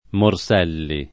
[ mor S$ lli ]